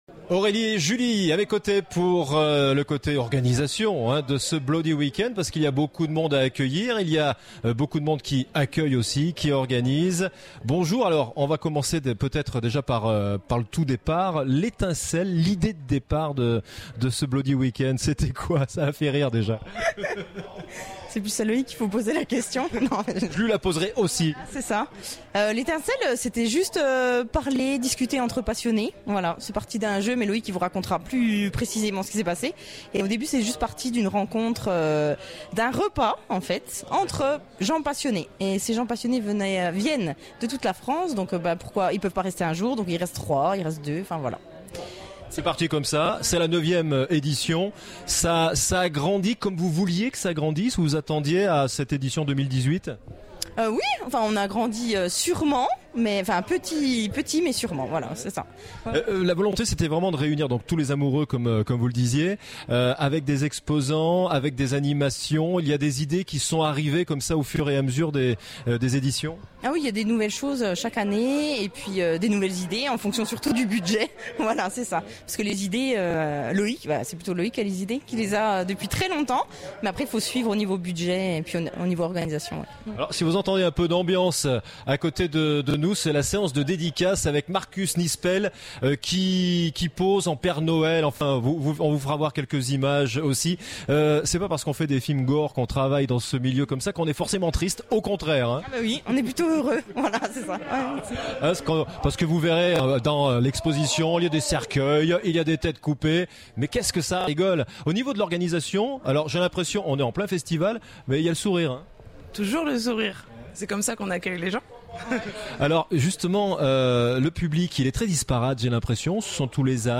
Interview au Bloody Week End 2018